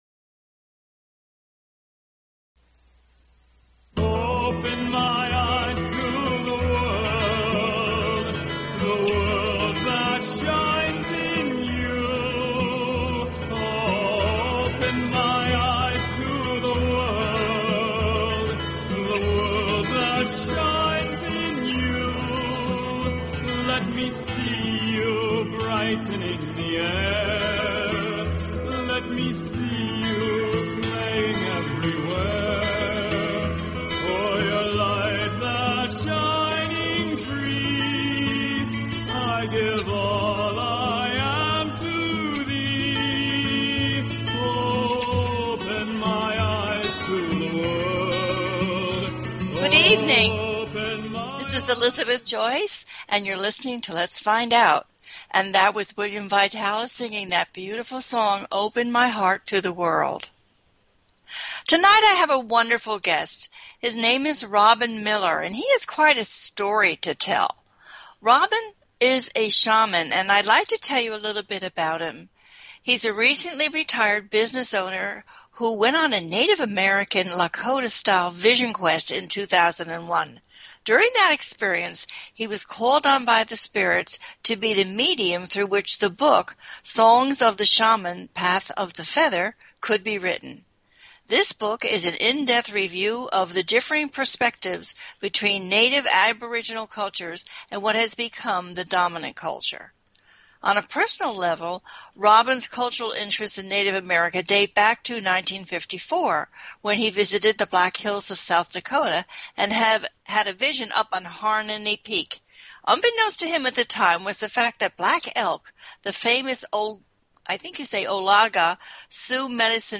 Talk Show Episode
The listener can call in to ask a question on the air.